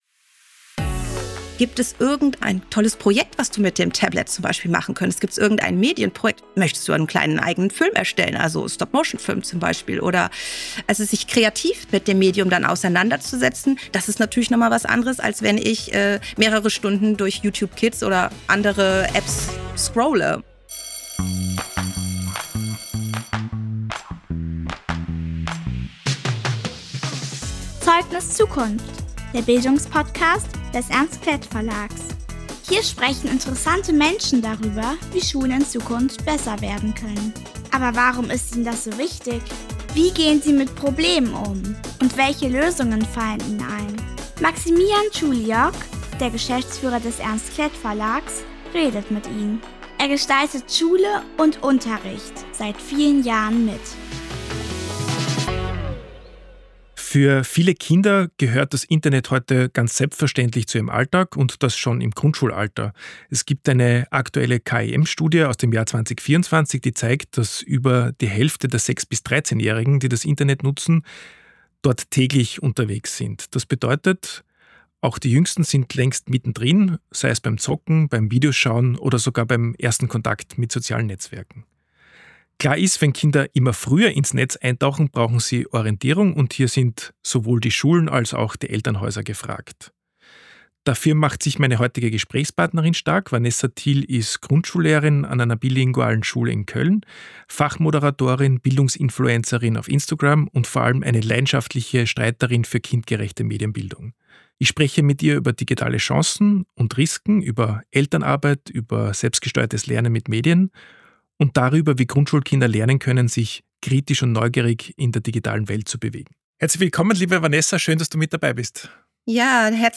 Ein offenes, inspirierendes Gespräch über Chancen, Risiken und warum Medienbildung kein Extra, sondern ein fester Bestandteil von Schule sein sollte.